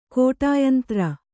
♪ kōṭāyantra